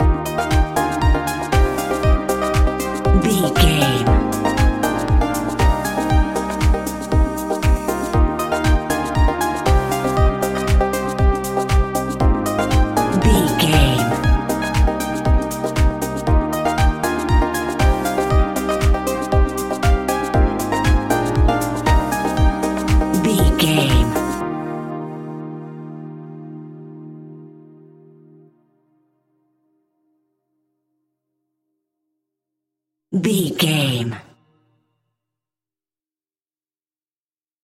Ionian/Major
E♭
uplifting
energetic
bouncy
electric piano
drum machine
synthesiser
electro house
funky house
synth bass